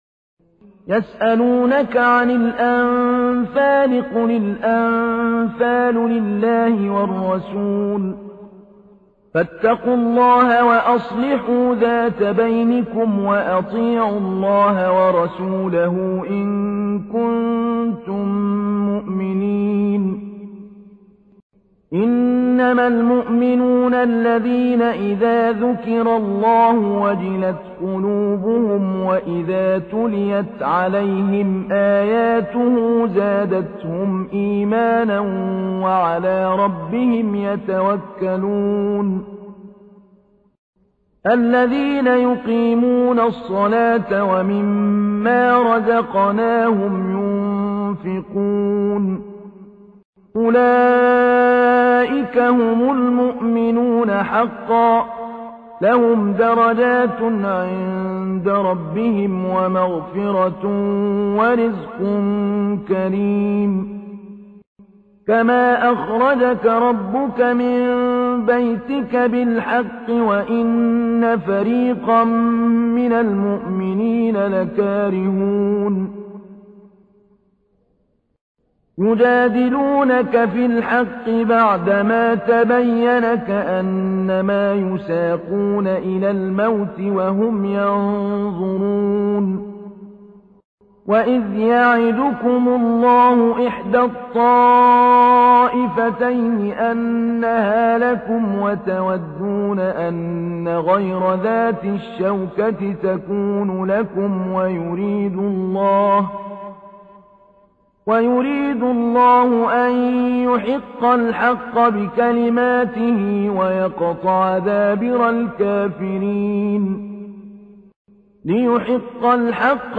تحميل : 8. سورة الأنفال / القارئ محمود علي البنا / القرآن الكريم / موقع يا حسين